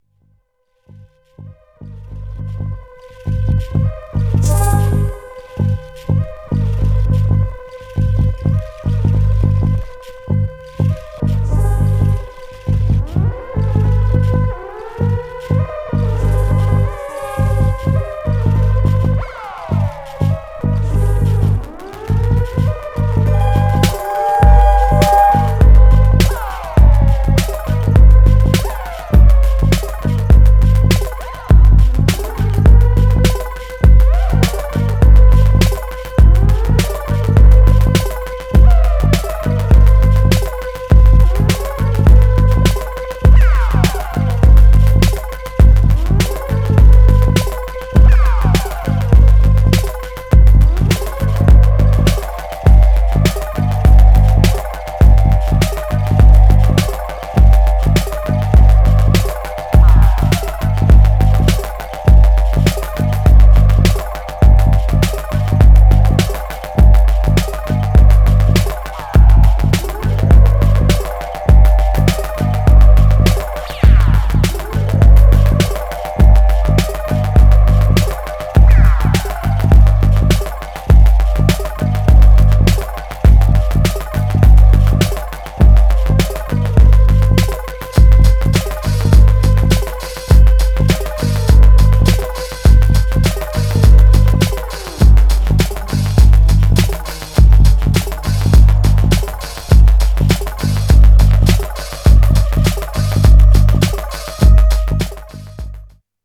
Styl: House, Techno, Breaks/Breakbeat Vyd�no